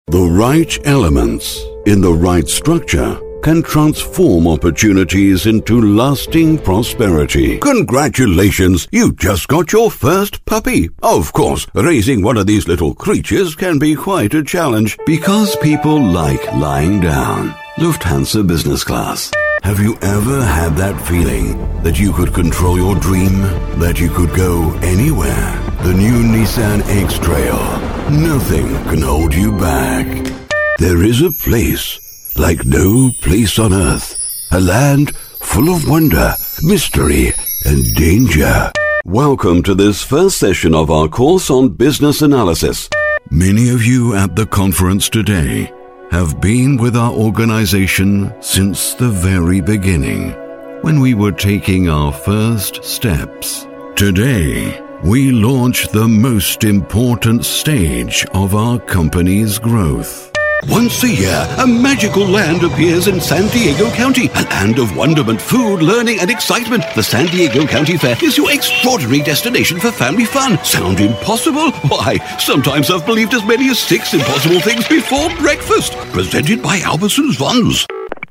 English Voice Over
We give voice to your voiceover and dubbing projects with the world's most famous native voice actors with American and British accents.